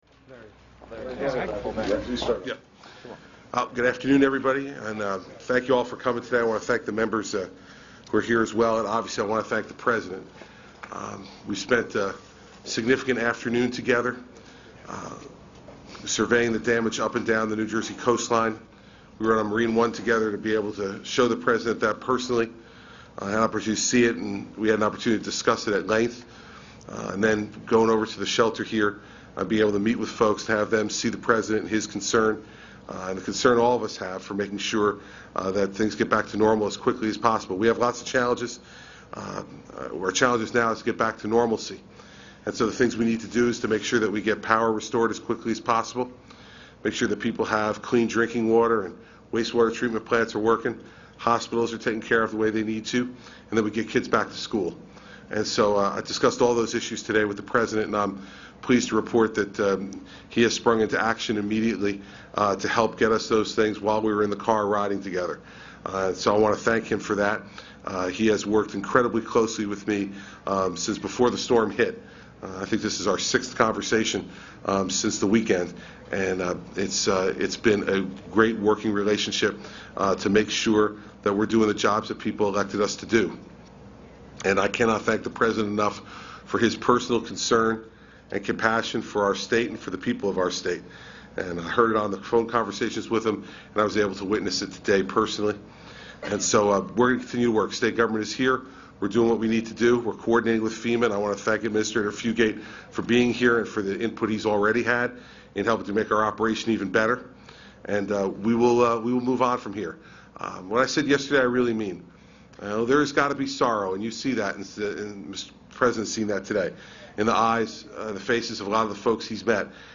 U.S. President Barack Obama and New Jersey Governor Chris Christie hold a press conference after touring storm damage near Brigantine, New Jersey